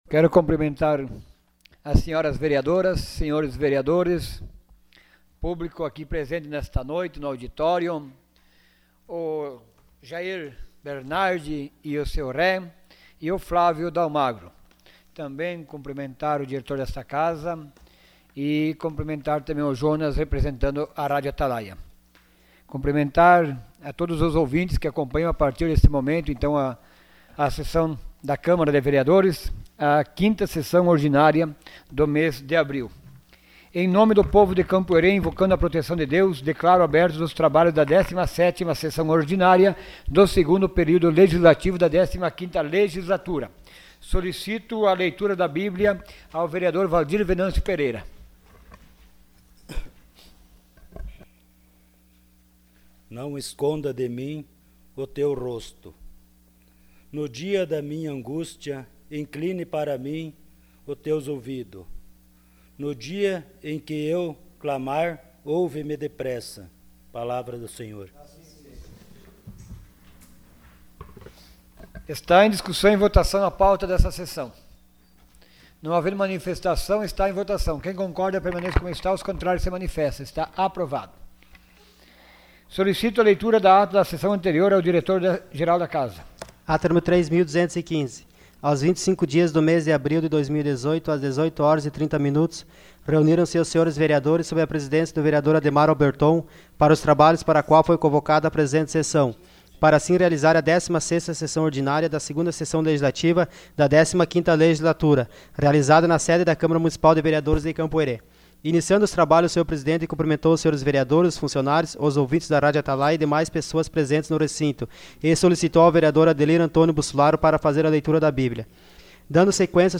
Sessão Ordinária dia 26 de abril de 2018.